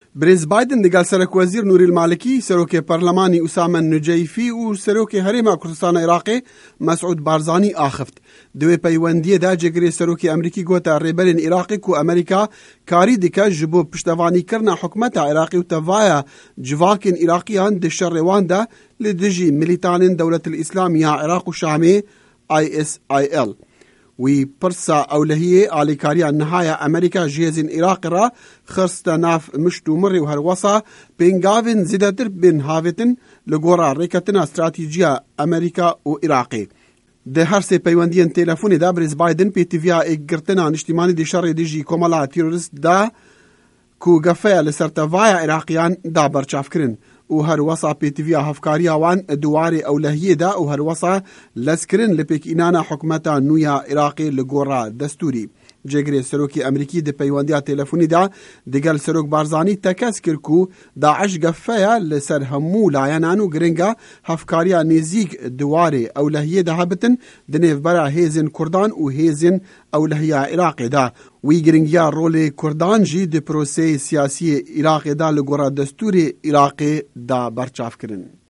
ڕاپـۆرتی عێراق